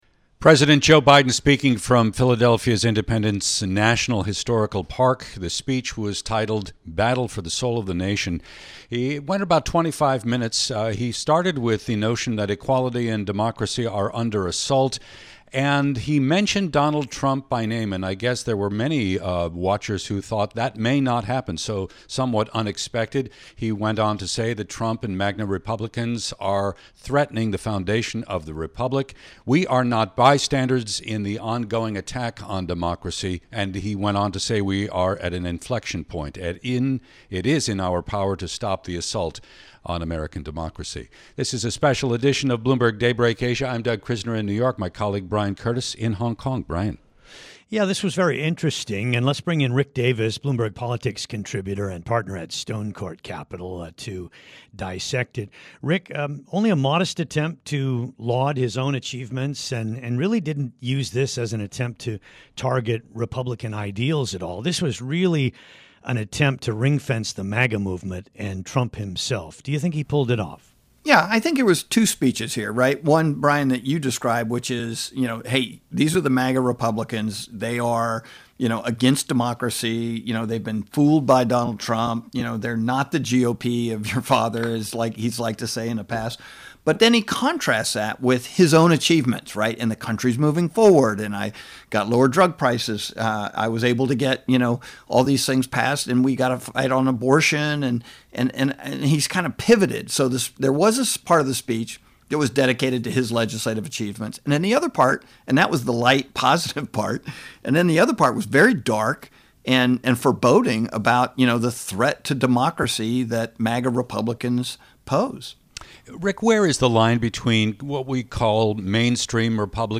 He spoke with hosts